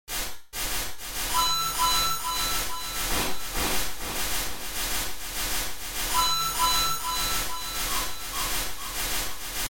城市环境声音
描述：城市交通（晚上10点），靠近印度浦那的交叉路口。
标签： 夜晚 背景音 白噪声 汽车 气氛 实地录音 人声 声景 交通 氛围 城市 环境音 一般噪音 噪声 城镇 街道 环境
声道单声道